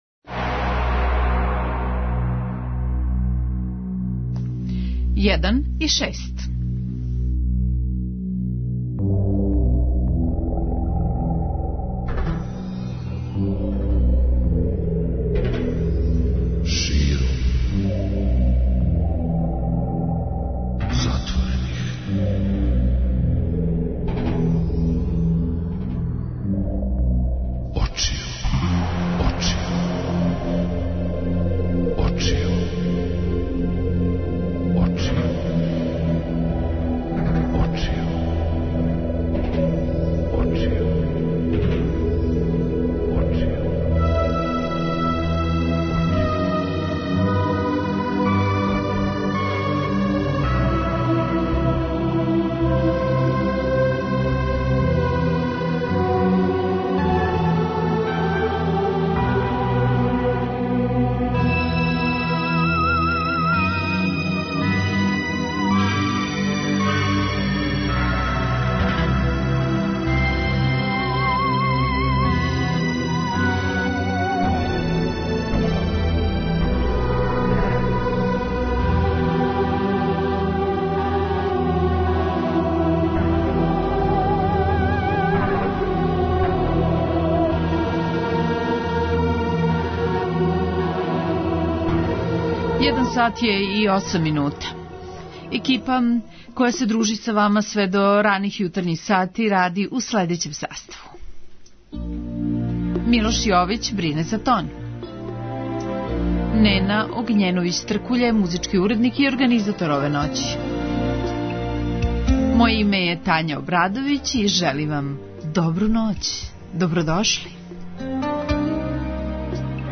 преузми : 31.06 MB Широм затворених очију Autor: Београд 202 Ноћни програм Београда 202 [ детаљније ] Све епизоде серијала Београд 202 Устанак Блузологија Свака песма носи своју причу Летње кулирање Осамдесете заувек!